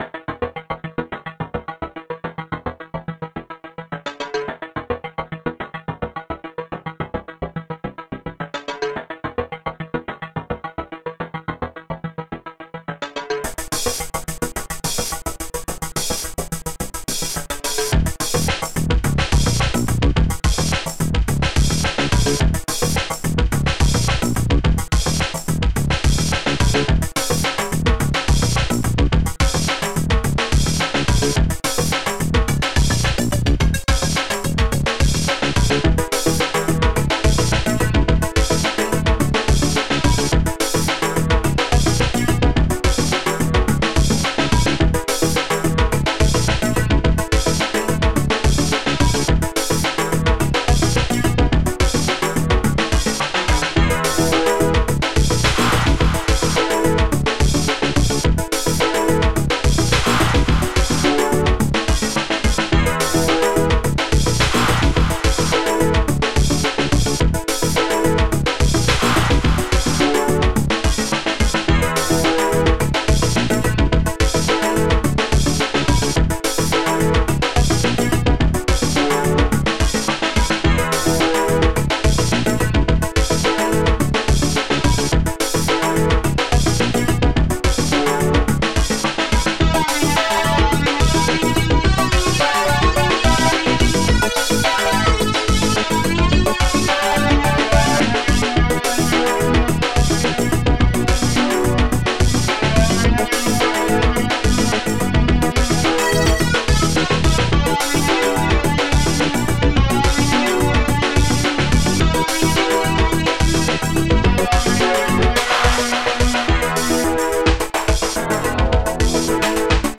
st-01:Buffsnare
st-01:basstune
ST-11:polysynth4
st-01:powerclap